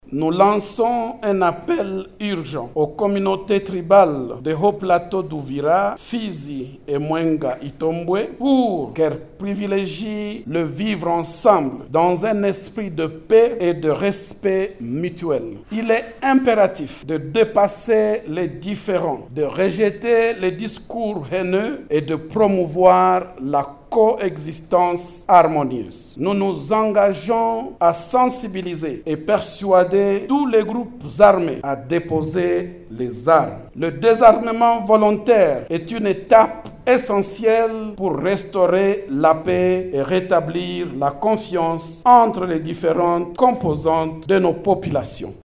Un extrait de leur déclaration est lu ici